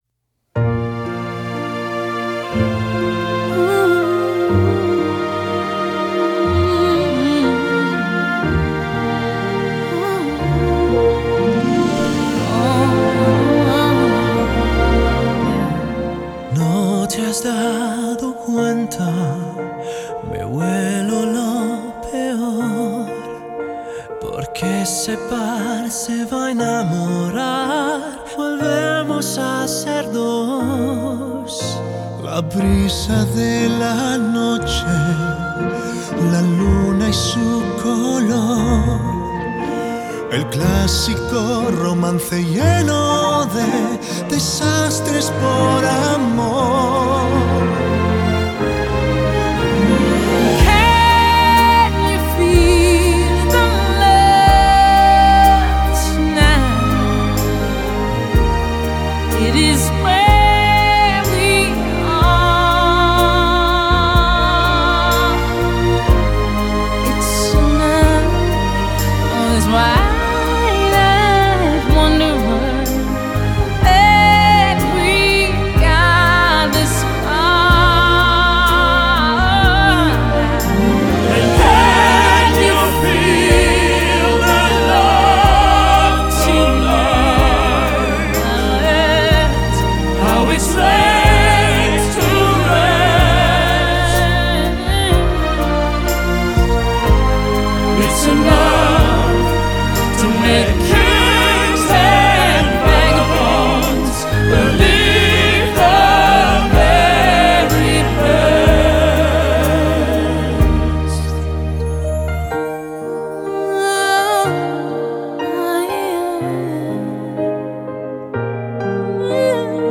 Genre: Pop, Crossover, Musical